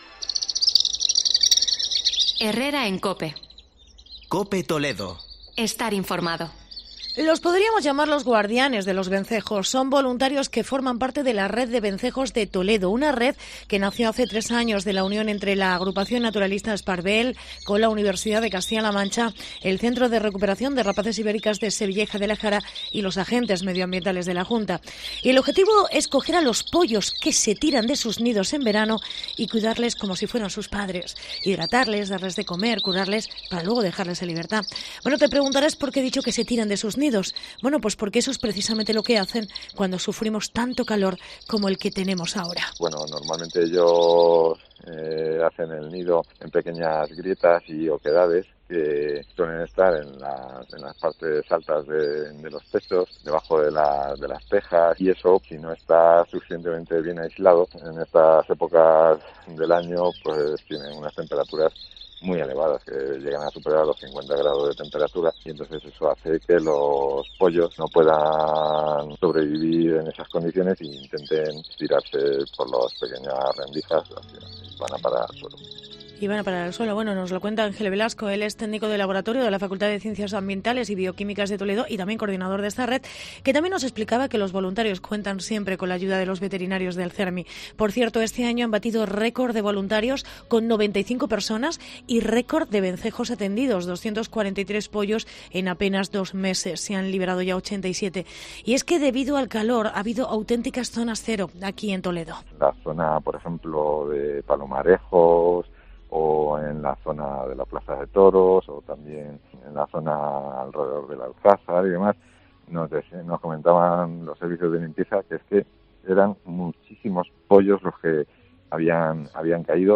Reportaje guardianes de vencejos